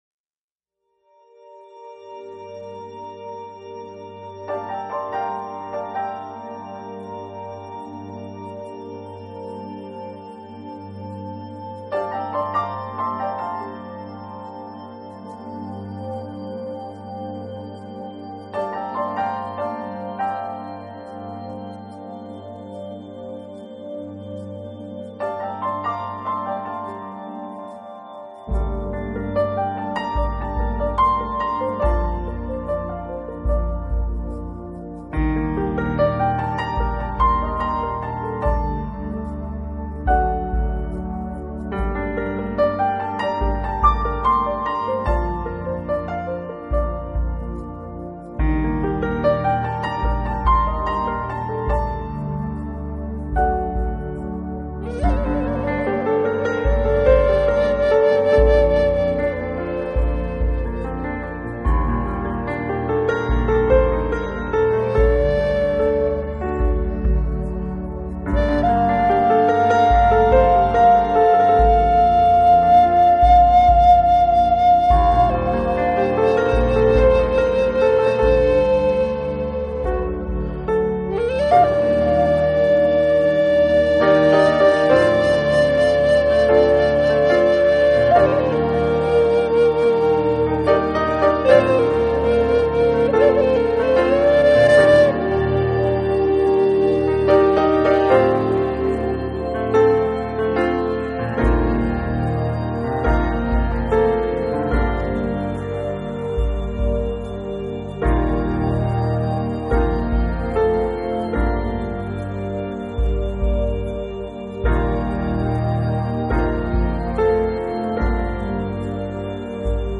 【纯音乐】
2001年合作的一张专辑，优美的旋律依然是那么动听，让听者感受到一份平和与宁静的